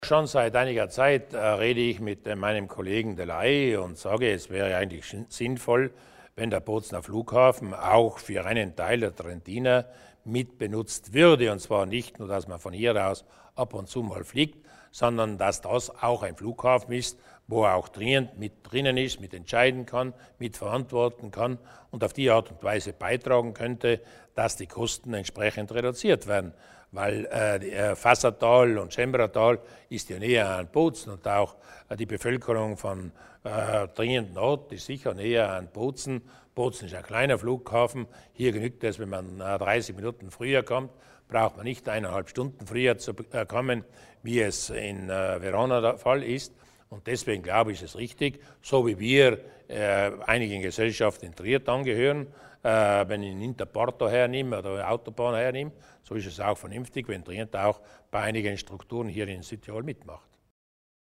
Landeshauptmann Durnwalder über die Zukunft des Flughafens in Bozen